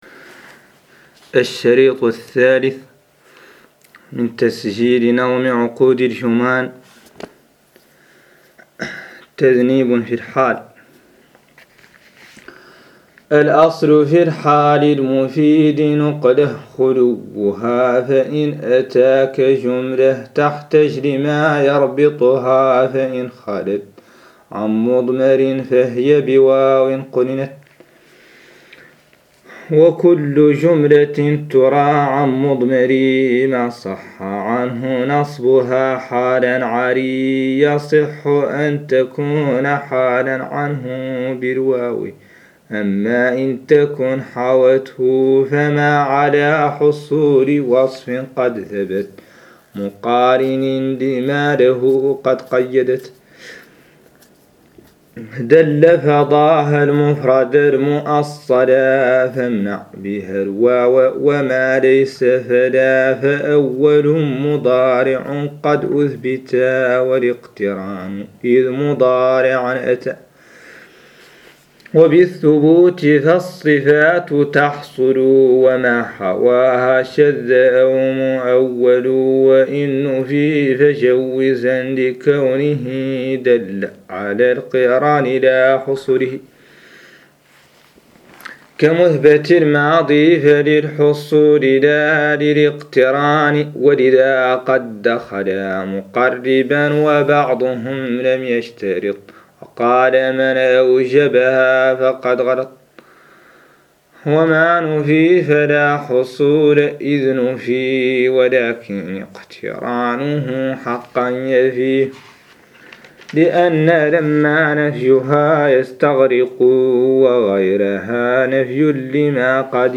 قراءة نظم عقود الجمان للسيوطي 03
ouqood-Juman-Reading03.MP3